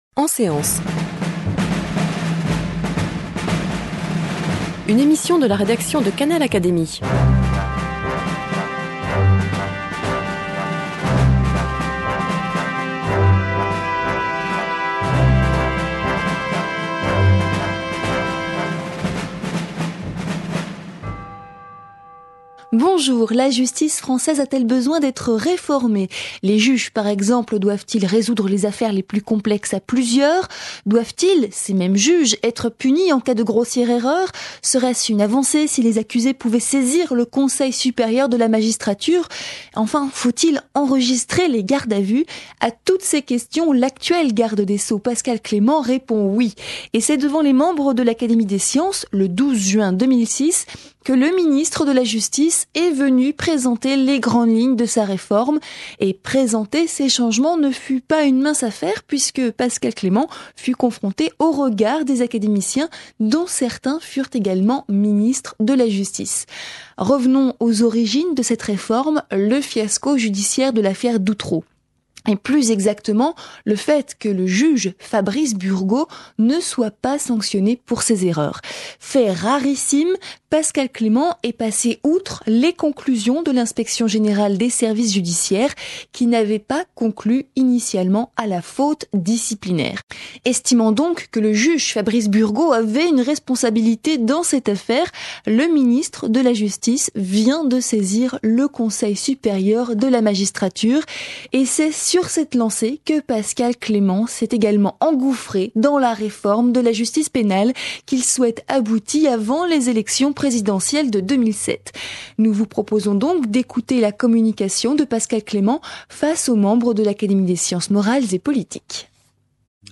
Le 12 juin 2006, le Garde des sceaux Pascal Clément est venu présenter devant les membres de l’Académie des sciences morales et politiques, les grandes lignes de sa réforme sur la justice pénale.